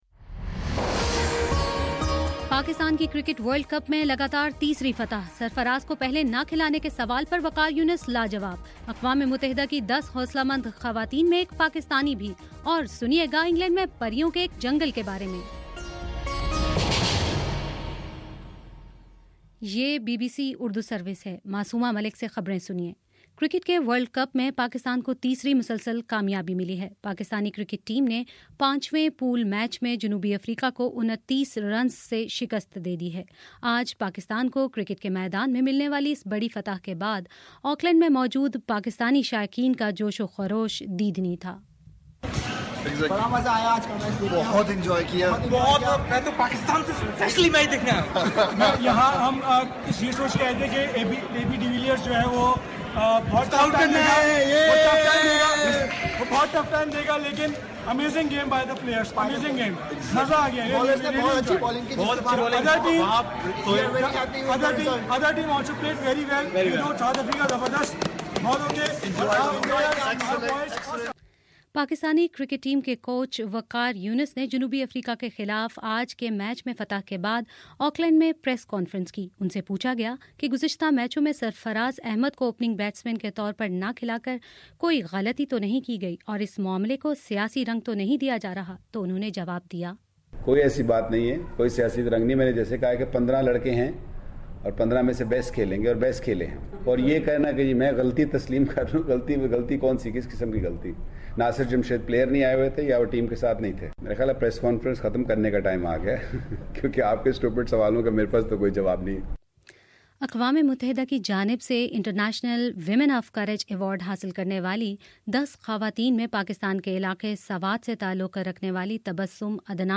مارچ 07: شام چھ بجے کا نیوز بُلیٹن